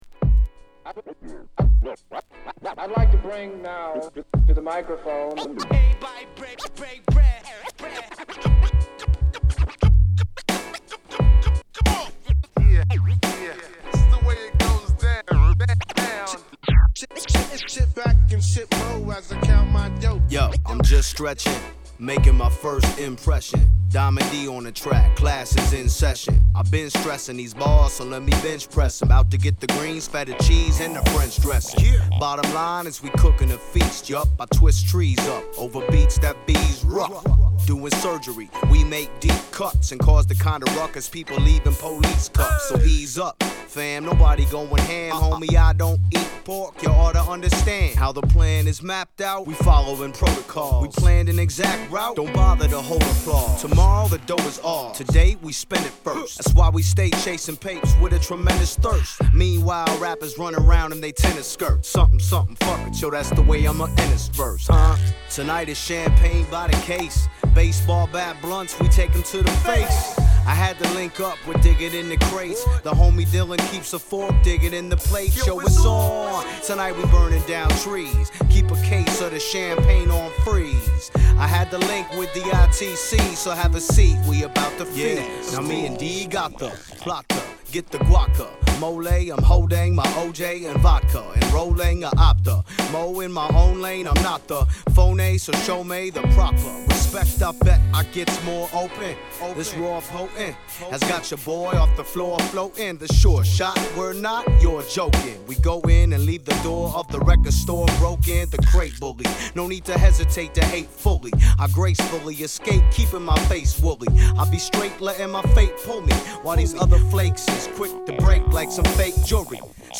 サイドBはインストです。